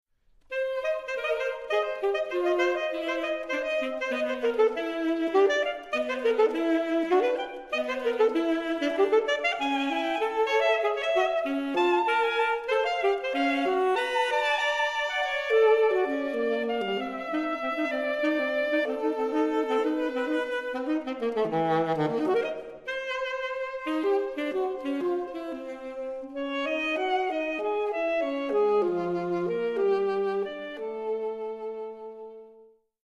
Alto Saxophone
saxophone